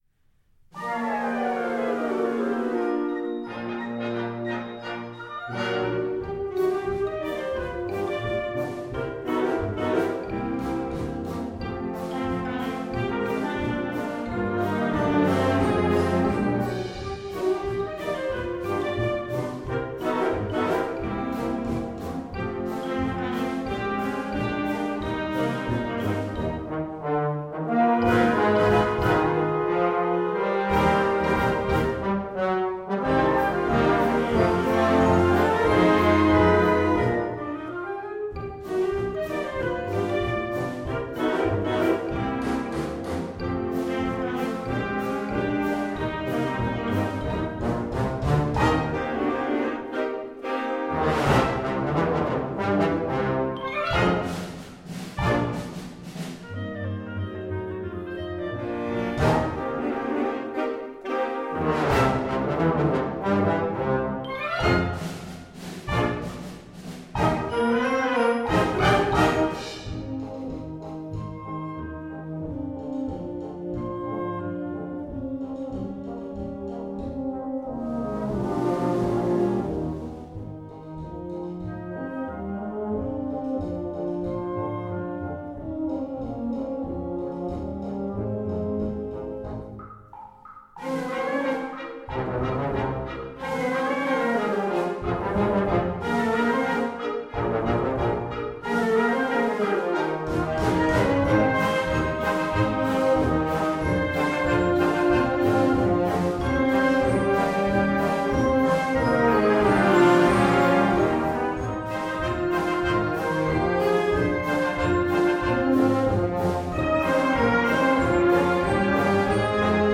Gattung: Konzertant
3:20 Minuten Besetzung: Blasorchester PDF